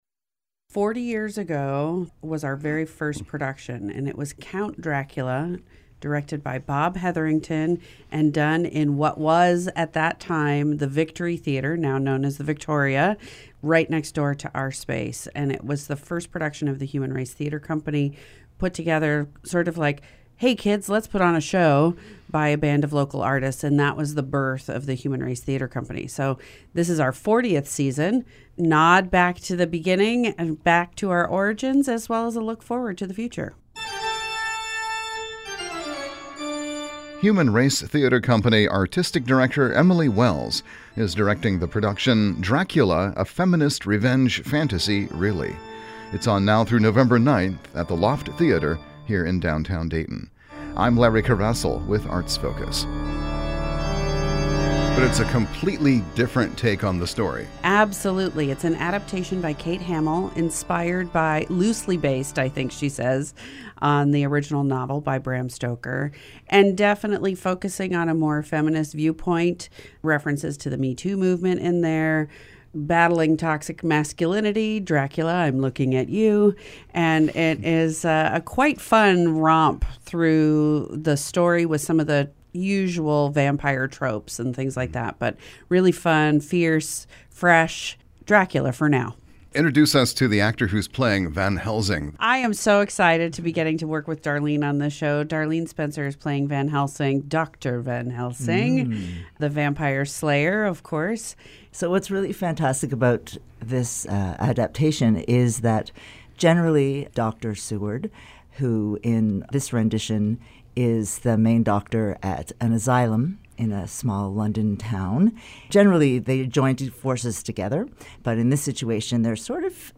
Each segment features a guest from the regional arts community discussing current activities, such as concerts, exhibitions and festivals.